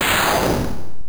snd_scytheburst_bc.wav